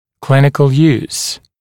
[‘klɪnɪkl juːs][‘клиникл йу:с]клиническое применение